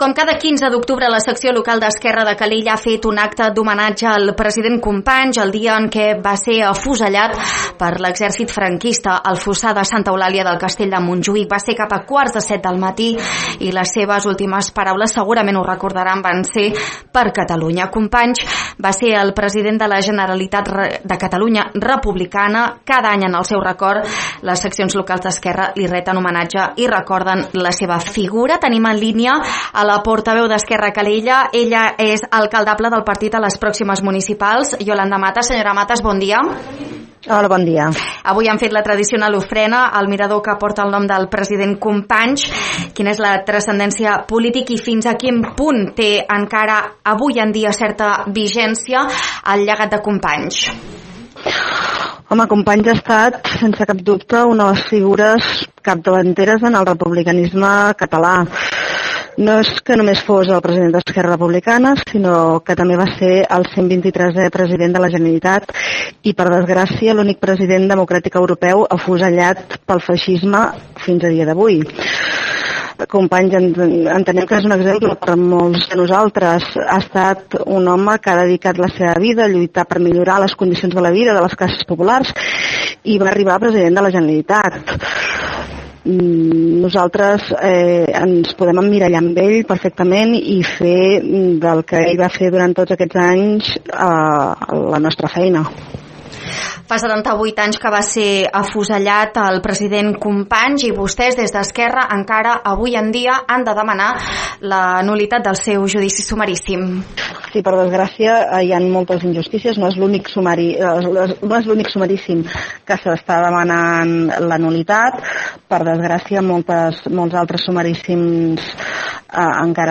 Marxa de clavells i ofrena floral al monument de Lluís Companys, al peu del Far de Calella.